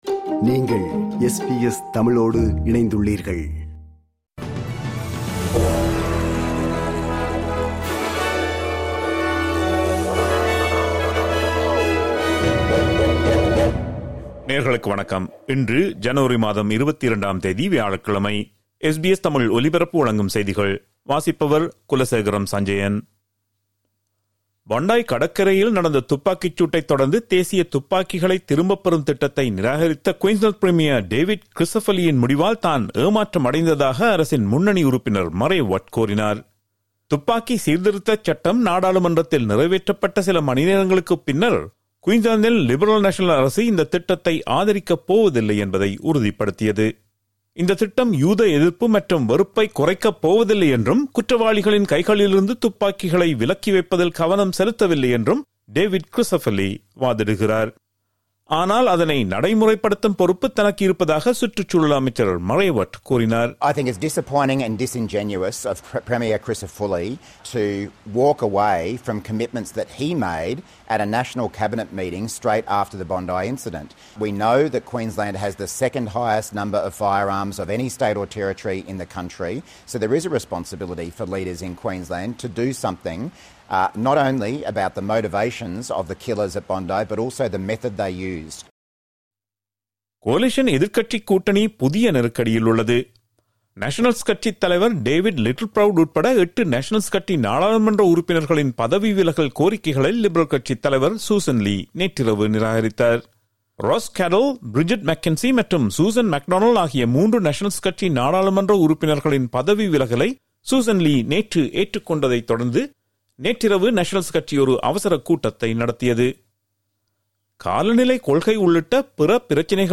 SBS தமிழ் ஒலிபரப்பின் இன்றைய ( வியாழக்கிழமை 22/01/2026) செய்திகள்.